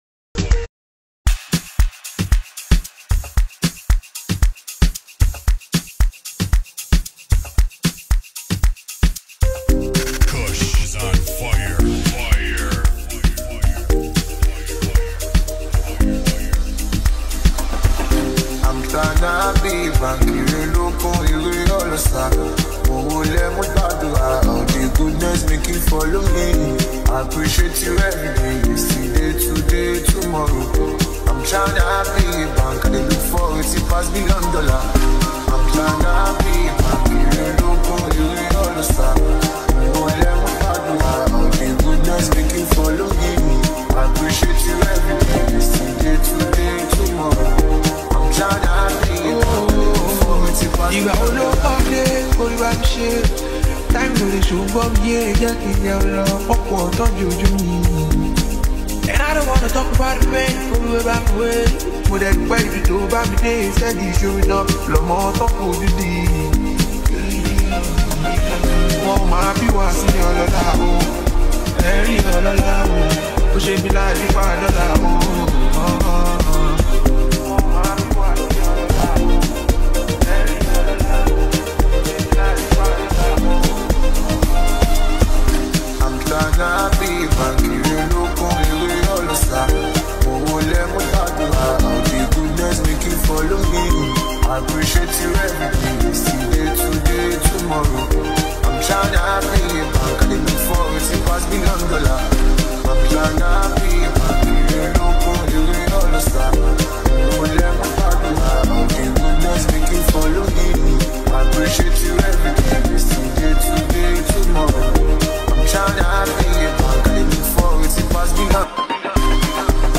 Exceptionally talented singer and songwriter